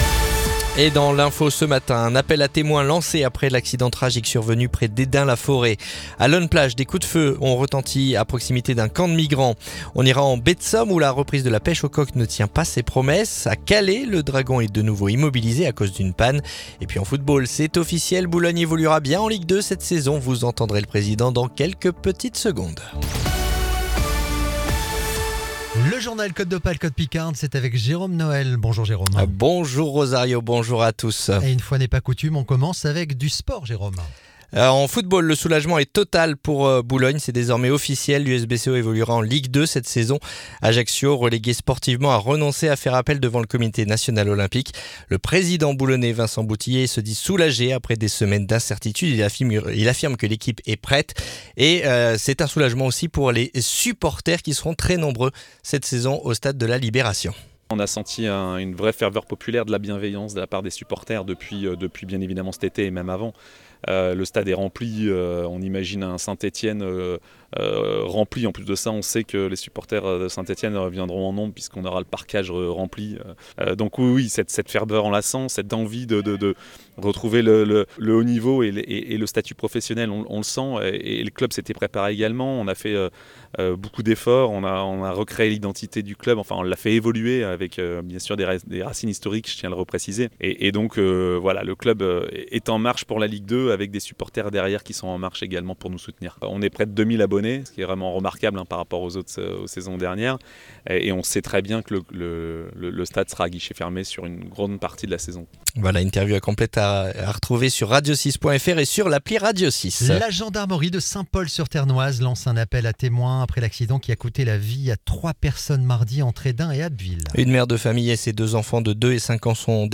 Le journal du jeudi 7 août